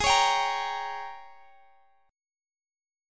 Listen to G#M7sus2sus4 strummed